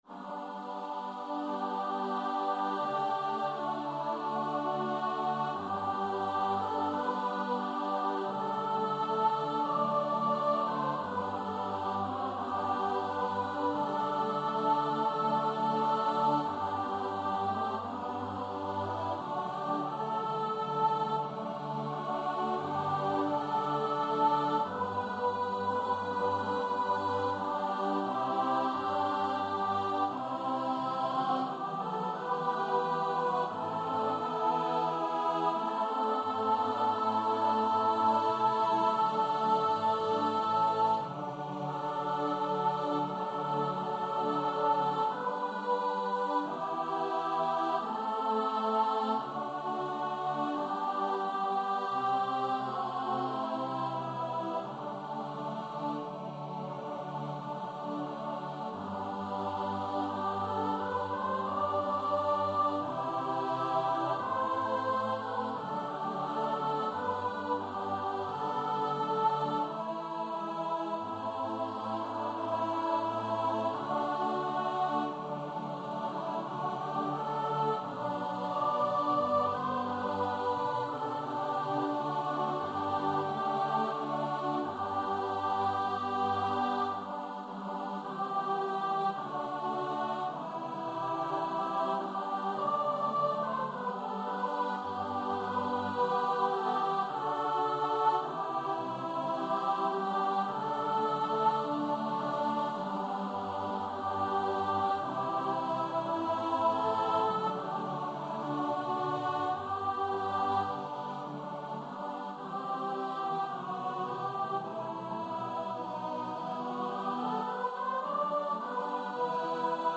Free Sheet music for Choir
4/2 (View more 4/2 Music)
G major (Sounding Pitch) (View more G major Music for Choir )
Choir  (View more Intermediate Choir Music)
Classical (View more Classical Choir Music)